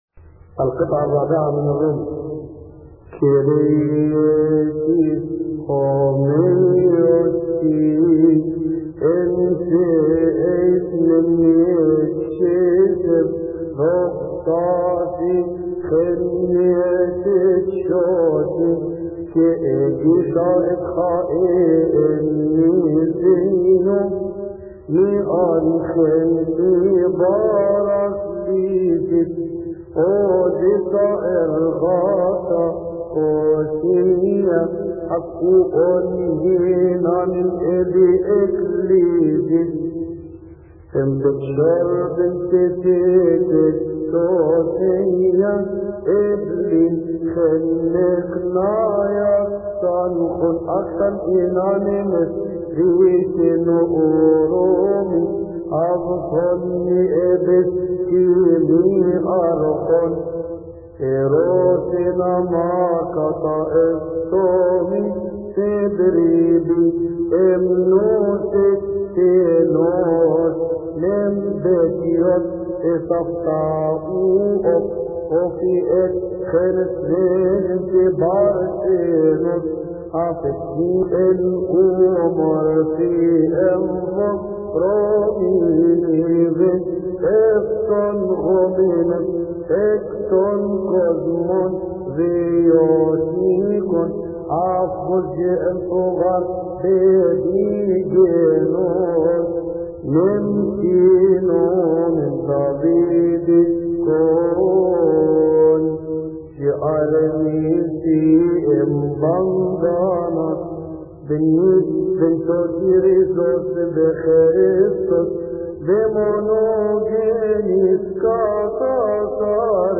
مكتبة الألحان
يصلي في تسبحة عشية أحاد شهر كيهك